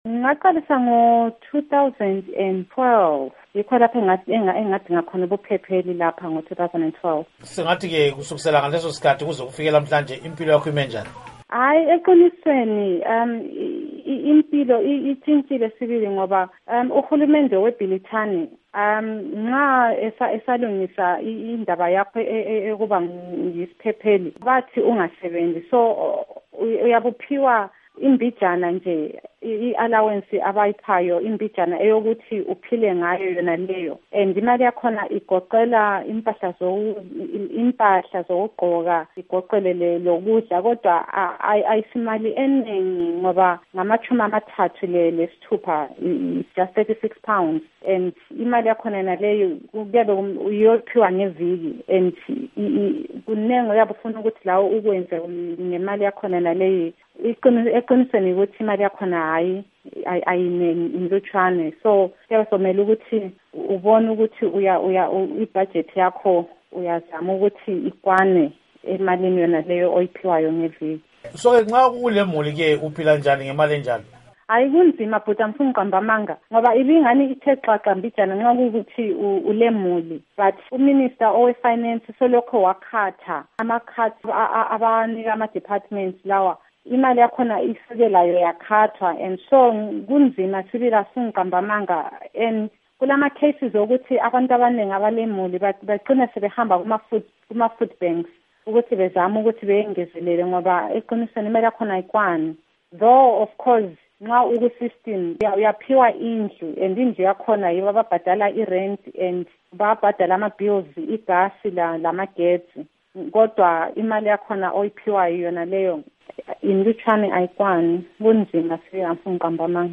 Kuhlelo lwezizalwane zeZimbabwe ezihlala emazweni, ngoLwesithathu sixoxe lesizalwane seZimbabwe esizama ukudinga amaphepha okuphephela eBhilithane.